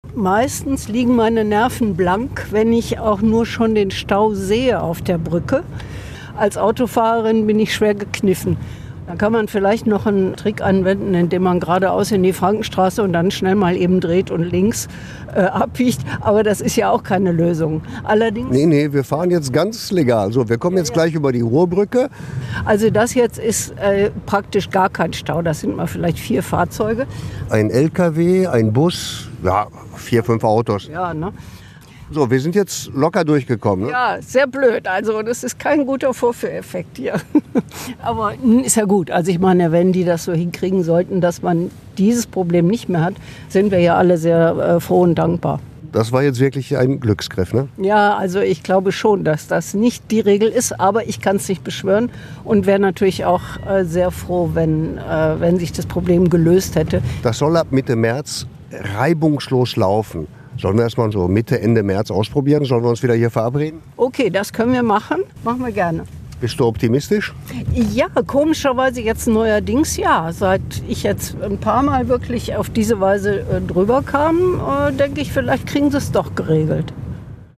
Test im Berufsverkehr